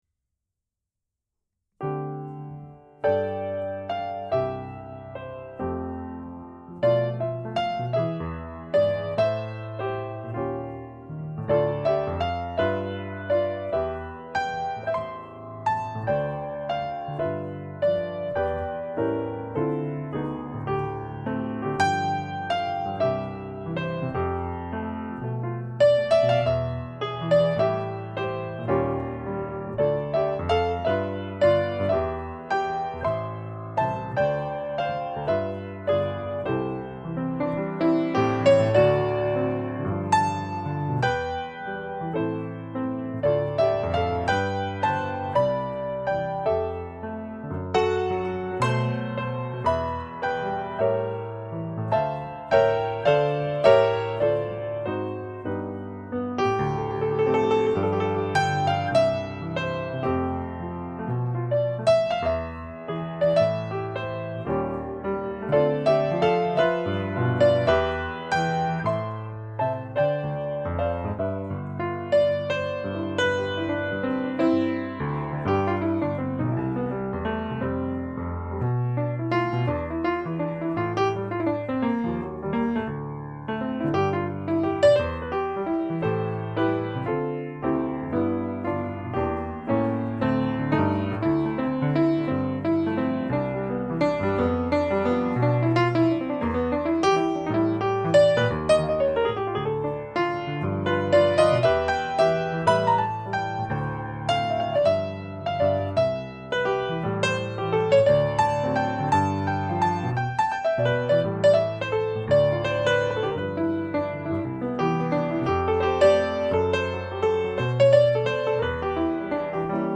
Five classic jazz standards.